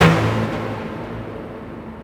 TM-88 Hit #02.wav